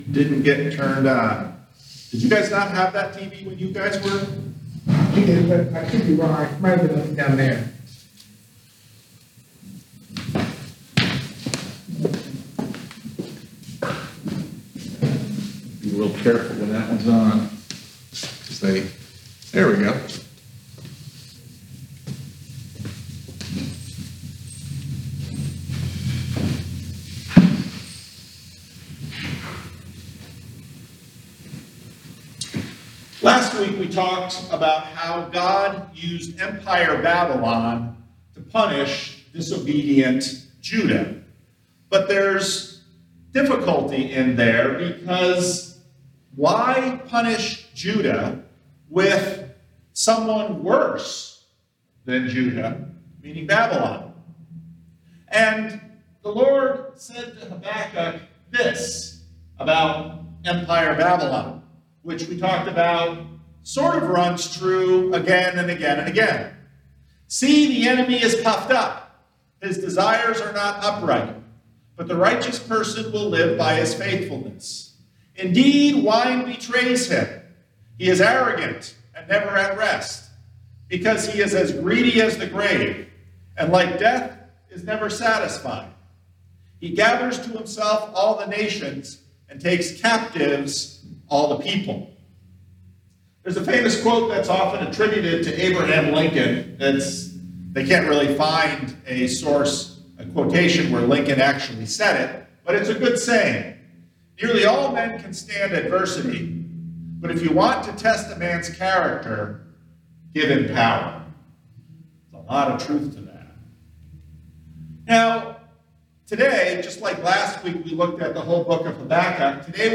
Sermons | Living Stones Christian Reformed Church